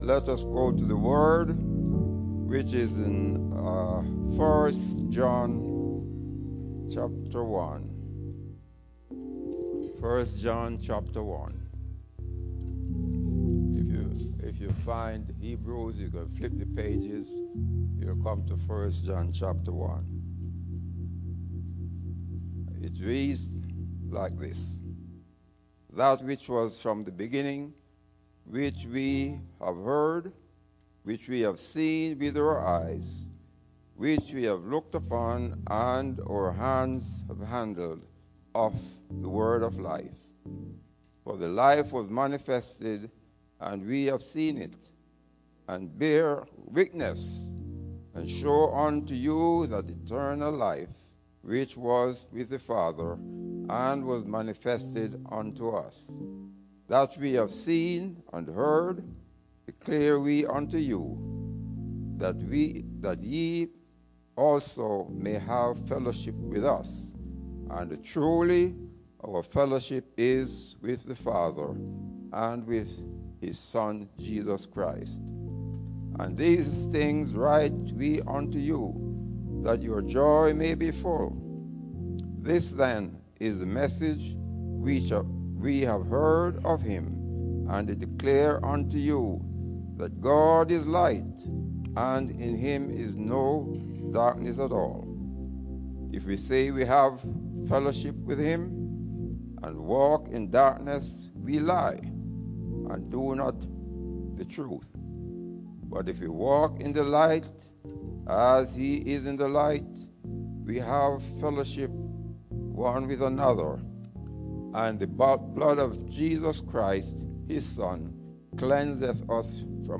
10:45 A.M. Service: Jesus Came at the Right Time